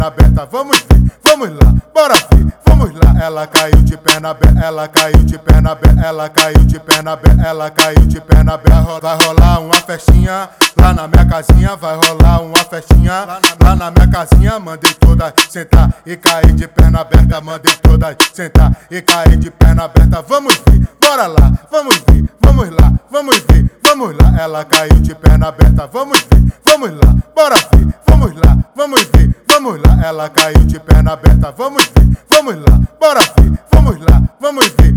Baile Funk Brazilian
Жанр: Фанк